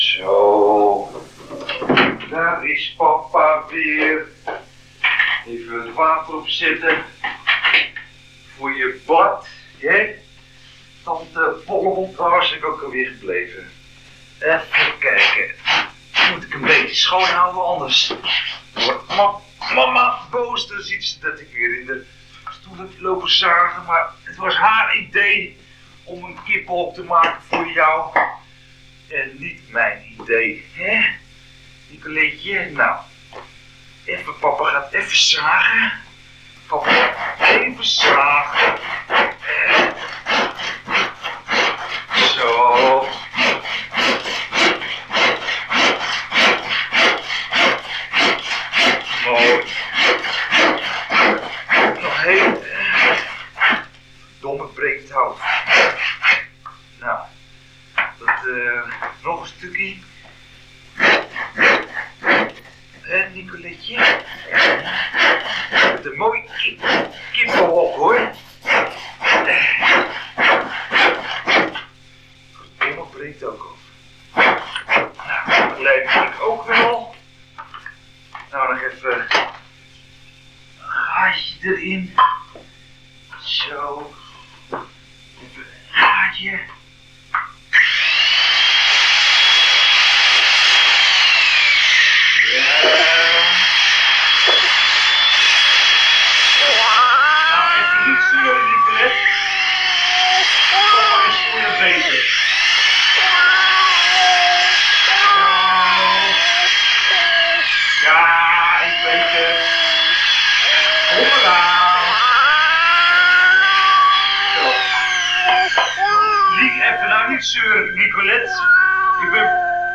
hoorspelen